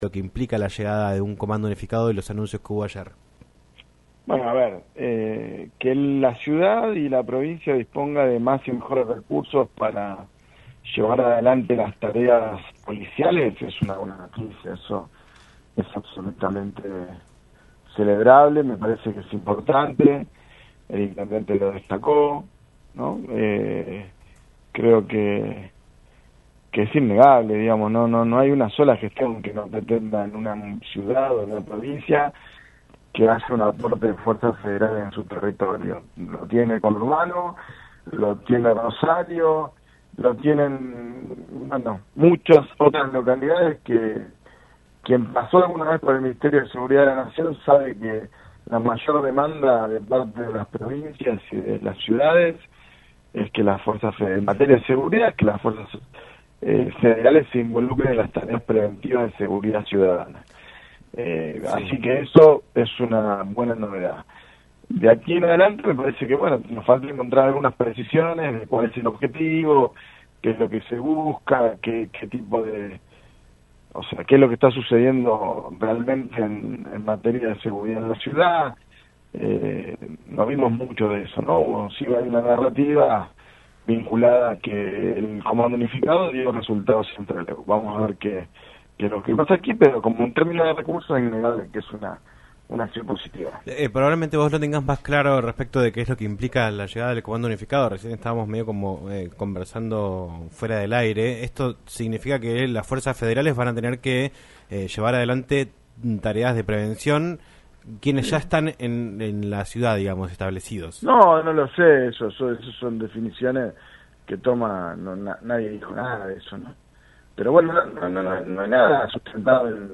José Glinski, diputado nacional por el bloque de Unión por la Patria, habló en "Un Millón de Guanacos" por LaCienPuntoUno sobre la implementación del Comando Unificado lanzado por el gobierno provincial. Además, se refirió al Comando de Seguridad para sostener la productividad que resaltó la ministra Bullrich en su visita a Comodoro y la agenda legislativa a nivel nacional.